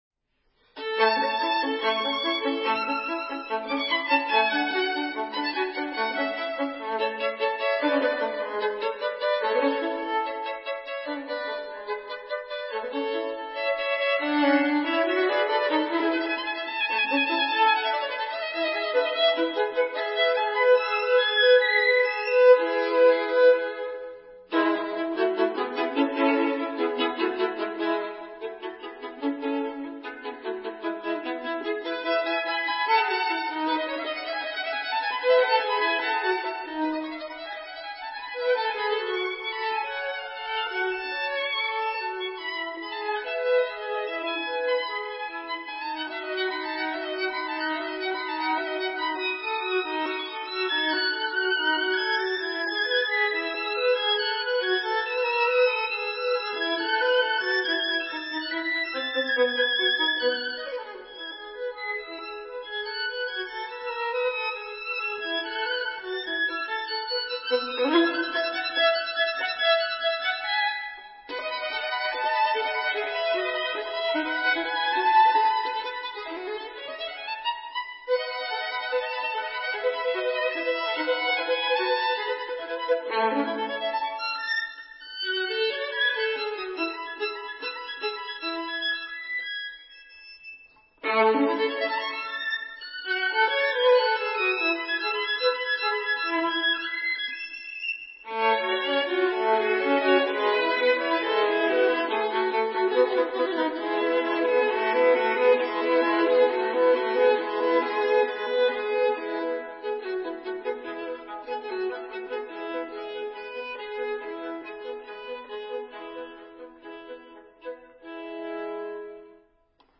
violon
Troisième sonate pour deux violons en la majeur